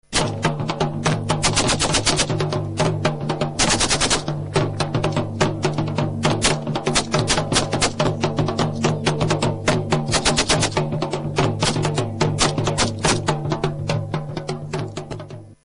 But only one elderly musician was found to play a double-sided Afghan drum called a dhol.
It was a sparse sound testifying to the state of music in southern Afghanistan immediately after Taliban rule.
Instead, militia fighters fired their AK-47s to the drumbeat in the way Western DJs use old records to perform "scratch" rhythms.
"Atan-i-Mili" performed with dhol and AK-47s